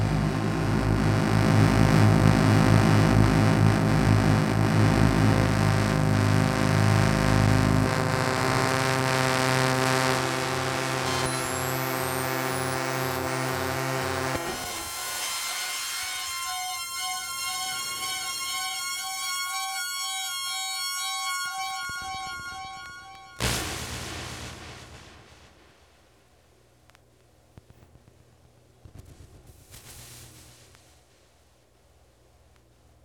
lmao i spoke too soon, shout out to skipback on the 404 for catching the moment it popped. not sure what went wrong here but im not too surprised
I’d sample this pop sound.